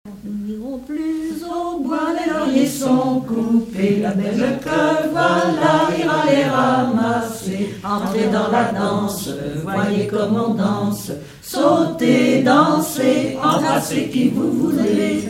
Mémoires et Patrimoines vivants - RaddO est une base de données d'archives iconographiques et sonores.
Rondes enfantines à baisers ou mariages
Pièce musicale inédite